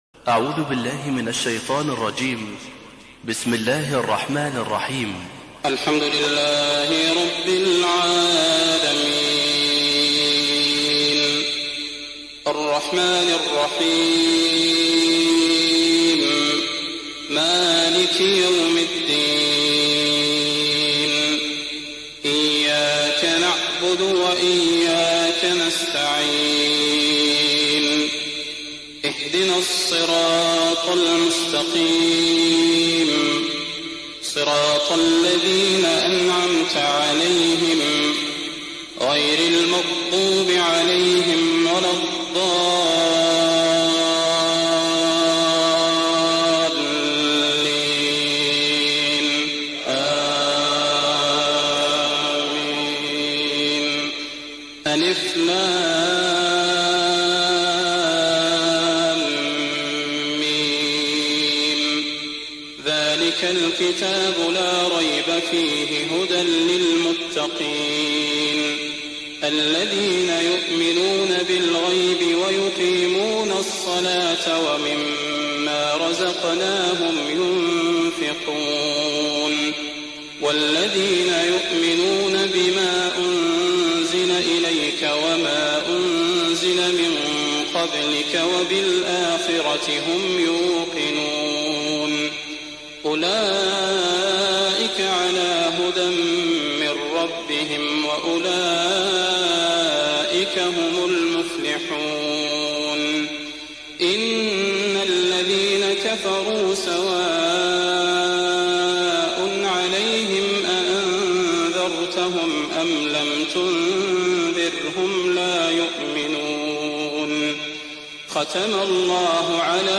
تهجد ليلة 21 رمضان 1419هـ من سورة البقرة (1-105) Tahajjud 21st night Ramadan 1419H from Surah Al-Baqara > تراويح الحرم النبوي عام 1419 🕌 > التراويح - تلاوات الحرمين